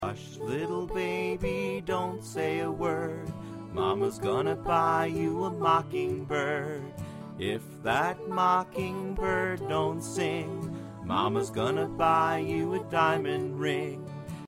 ▪ Vocal Mp3